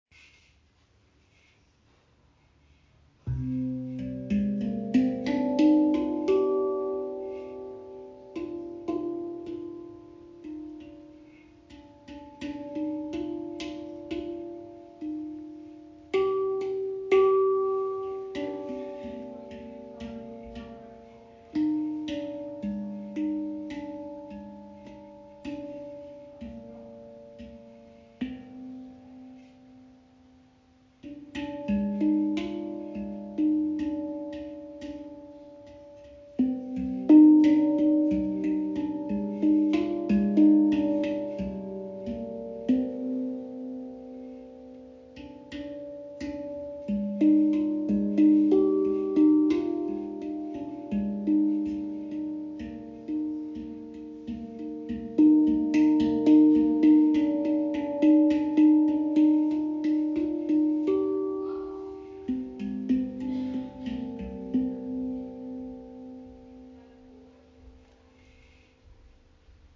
Entdecke die Chrip Handpan aus Indien: handgefertigt, mit klarem, ausgewogenem Klang und inklusive originaler Transporttasche für sicheren Transport.
Klangbeispiel
Jedes Instrument wird sorgfältig von Hand gestimmt und überzeugt durch klare Klänge und harmonische Obertöne.
Handpan Chirp | C Kurd in 432 Hz| 11 Klangfelder | inkl. Rucksacktasche Sorgfältig handgefertigte Handpan aus Indien mit klarem, ausgewogenem Klang.